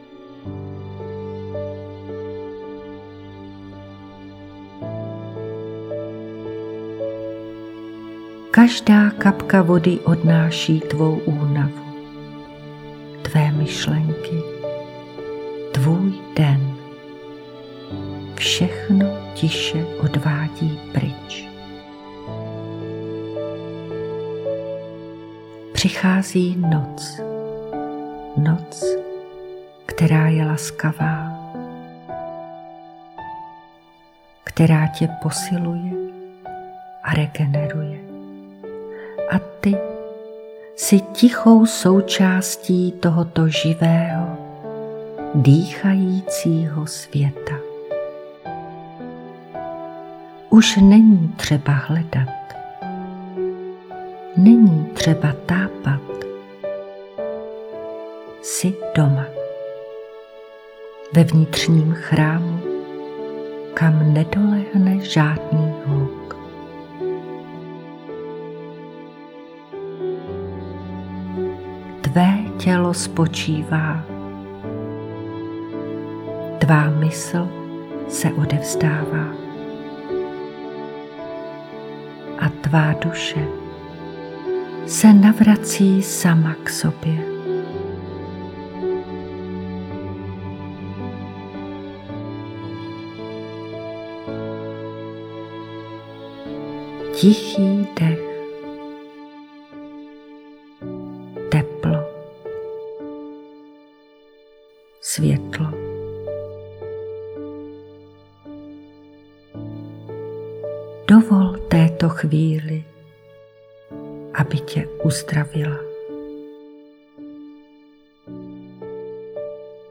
Jemně vás ukolébá do hlubokého uvolnění, v němž se sny mění v poselství a noc se stává posvátným prostorem.
Ukázka z meditace: Celková délka 00:37:22